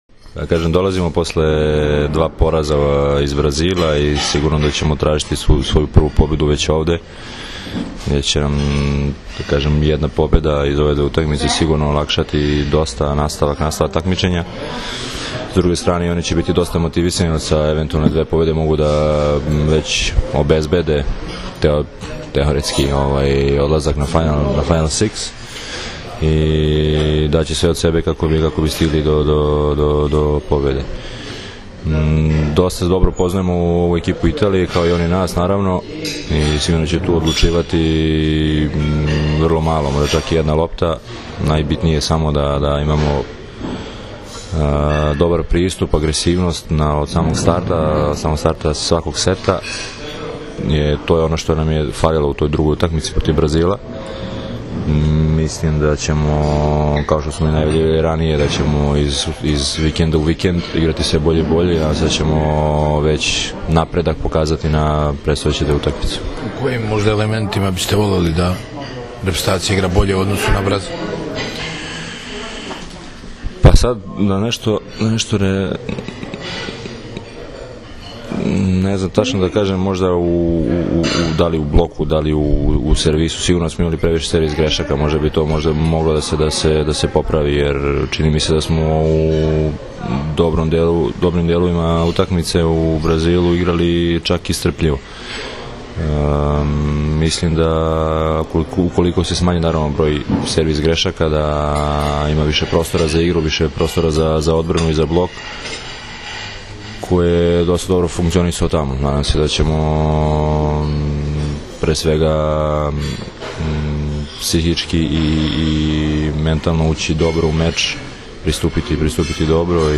U Pezaru je danas održana konferencija za novinare uoči prve utakmice II vikenda A grupe XXVI Svetske lige 2015. između Srbije i Italije, kojoj su prisustvovali kapiteni i treneri obe selekcije: Dragan Stanković i Nikola Grbić, odnosno Dragan Travica i Mauro Beruto.
IZJAVA DRAGANA STANKOVIĆA